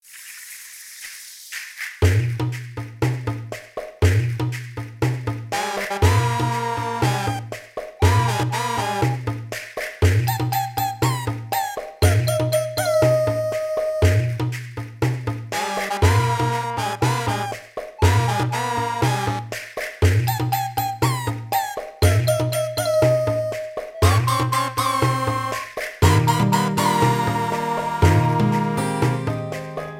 Title screen theme
Ripped
Trimmed file to 30 seconds, applied fadeout